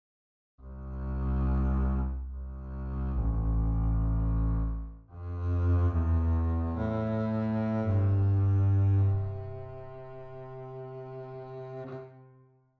Contrabbasso (Double bass, Contrabbass, Bass)
Il Contrabbasso è usato per sostenere la parte più bassa del registro dell’orchestra, un’ottava sotto quella del Violoncello. Può creare delle cupe atmosfere dark ed è perfetto per quelle lugubri.
Range: Il Contrabbasso è lo strumento ad arco di dimensioni maggiori e quello con il range più ristretto: dalla E0 (esteso nella maggior parte delle library fino a C0) a C3.
Contrabbasso.mp3